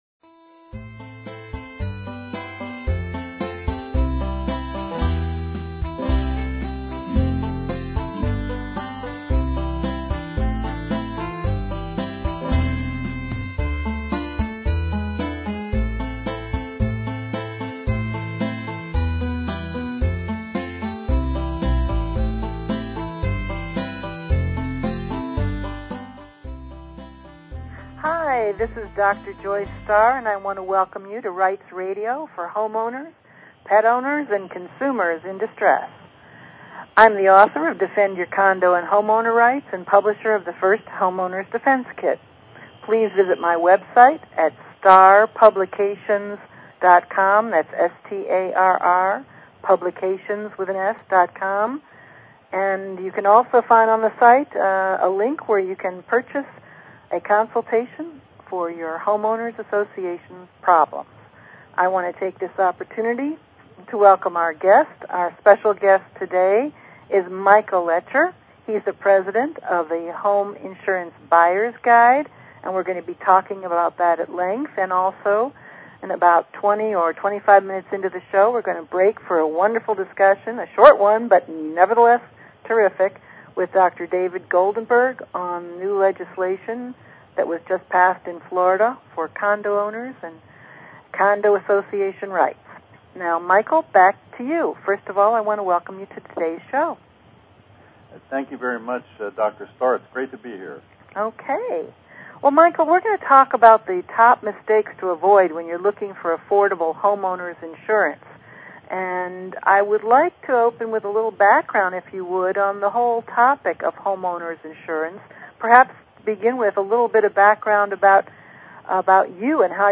Talk Show Episode, Audio Podcast, Rights_Radio and Courtesy of BBS Radio on , show guests , about , categorized as